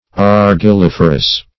Search Result for " argilliferous" : The Collaborative International Dictionary of English v.0.48: Argilliferous \Ar`gil*lif"er*ous\, a. [L. argilla white clay + -ferous.]
argilliferous.mp3